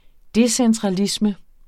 Udtale [ ˈdesεntʁɑˌlismə ]